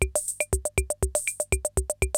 CR-68 LOOPS2 3.wav